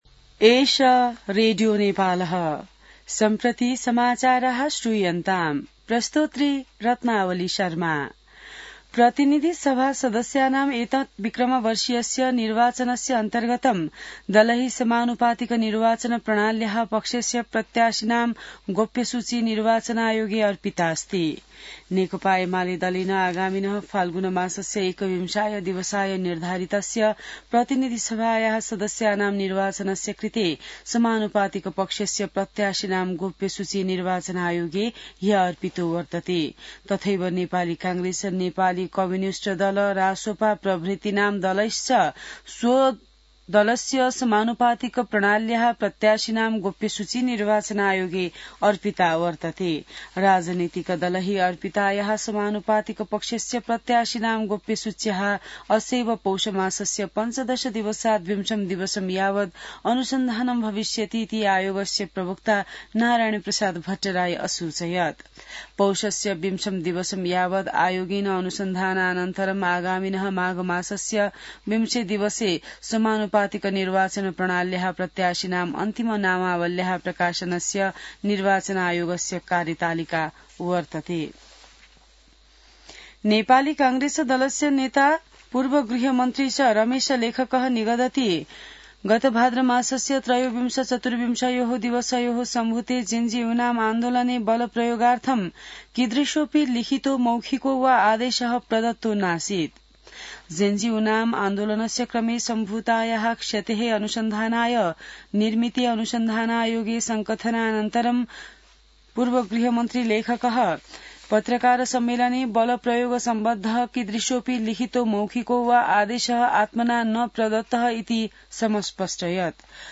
संस्कृत समाचार : १५ पुष , २०८२